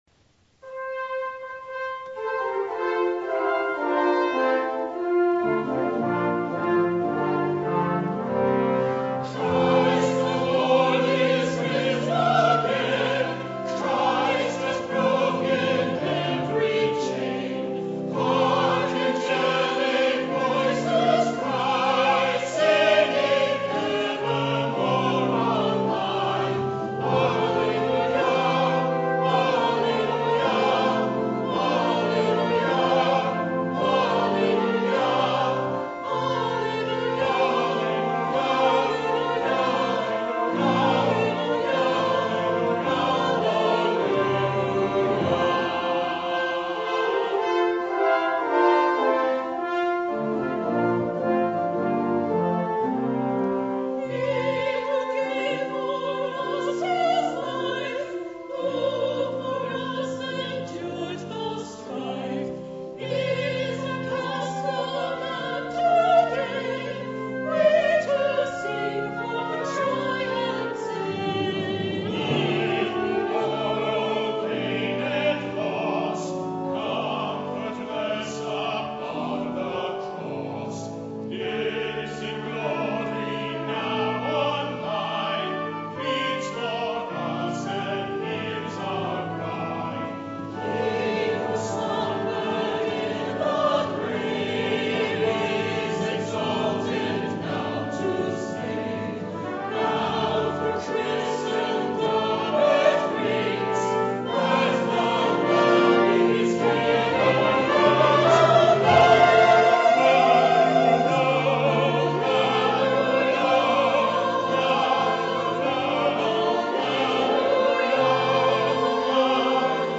Anthems